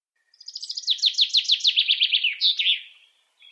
Bird Chirping